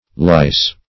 Lice \Lice\ (l[imac]s), n.;